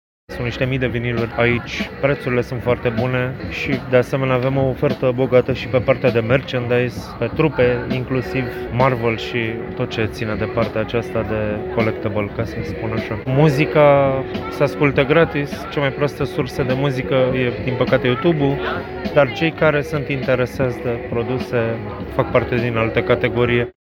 Târgul de Carte Gaudeamus Radio România din Piața Sfatului din Braşov, poate fi vizitat, până duminică, în intervalele orare 9 – 14 și 16 – 21.